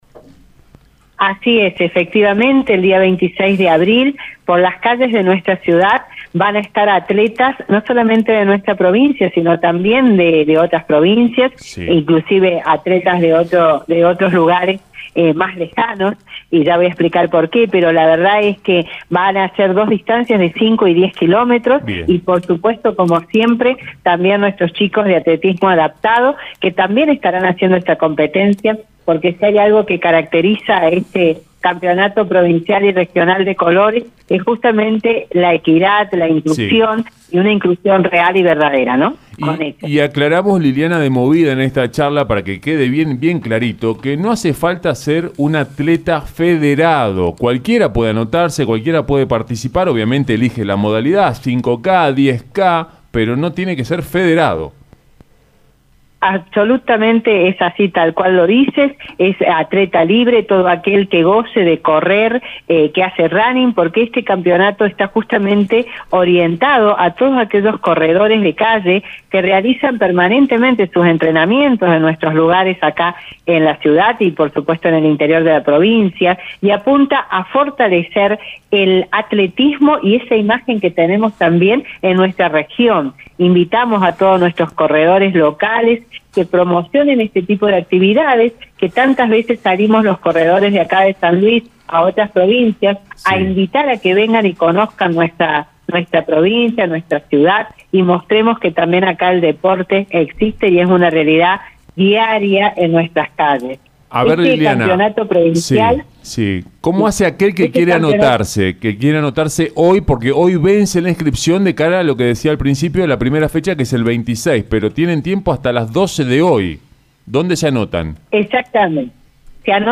En una entrevista exclusiva